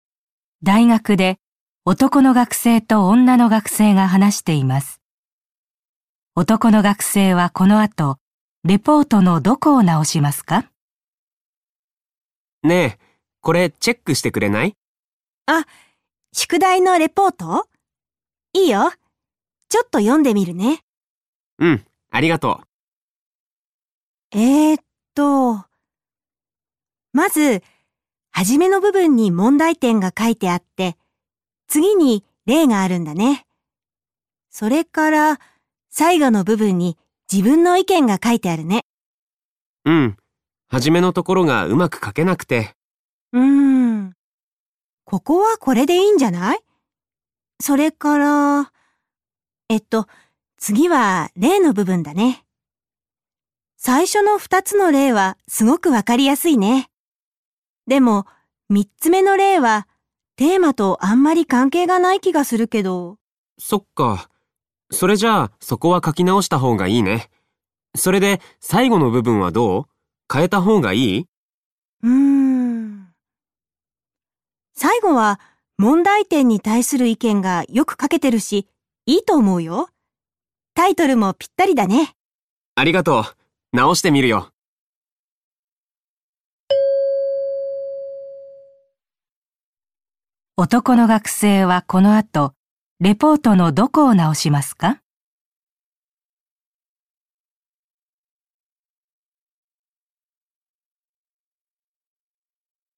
聴解
問題１：このもんだいでは、まず質問を聞いてください。それからはなしを聞いて、問題用紙の1から4の中から、正しい答えを一つえらんでください。